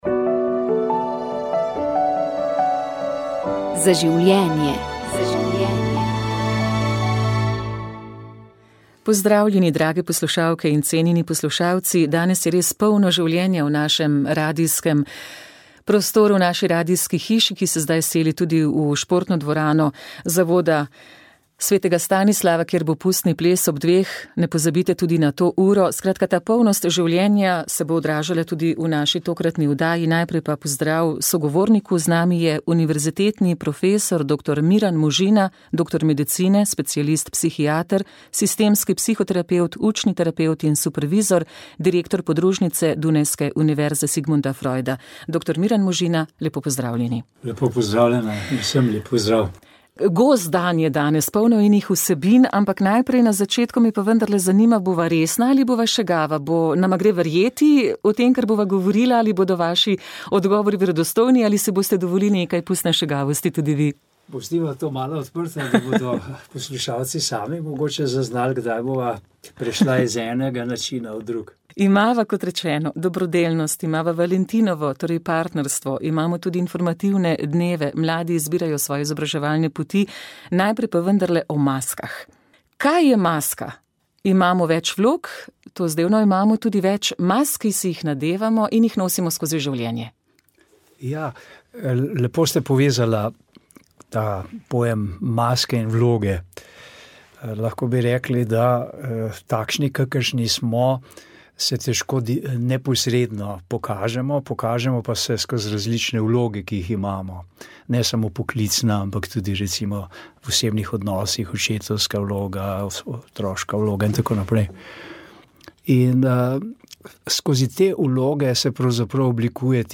Molitev je vodil ljubljansk pomožni škof Franc Šuštar.